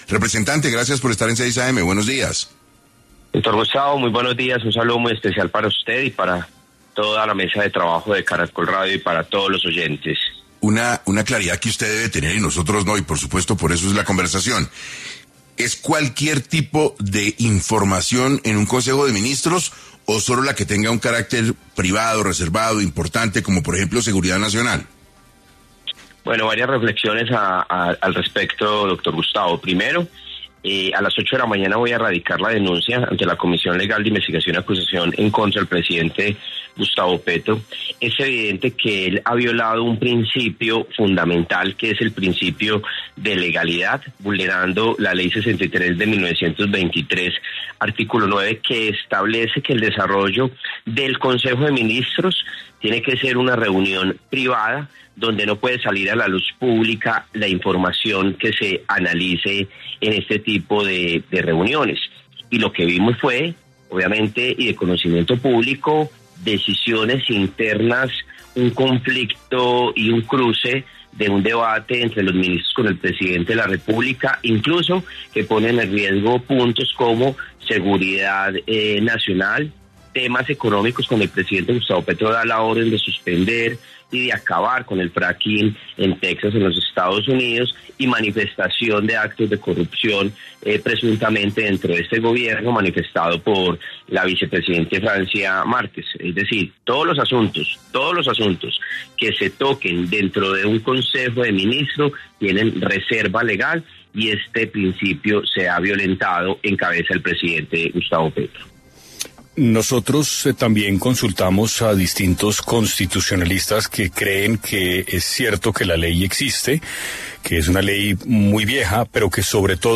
El representante Juan Espinal explicó en 6AM cuál fue la ley que el mandatario habría violado al transmitir el encuentro ministerial.